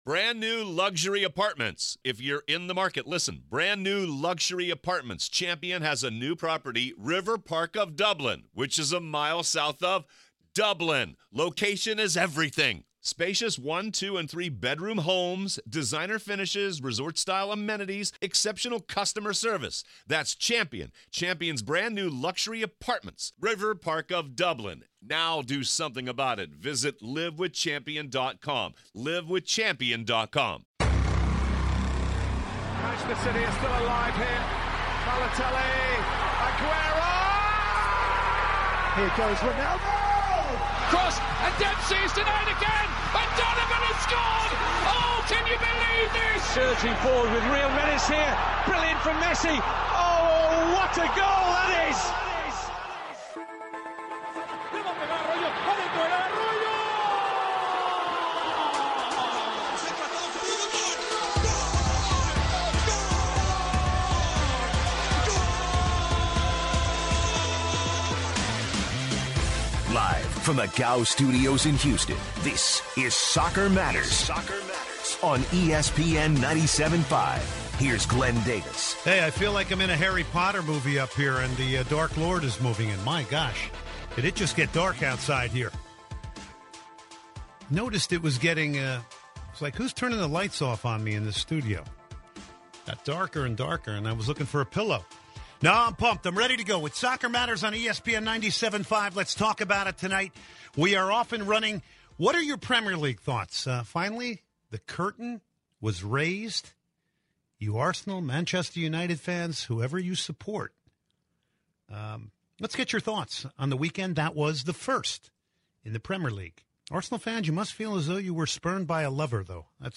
takes calls about EPL Week 1, Argentina vs Bolivia coming to Houston, and much more.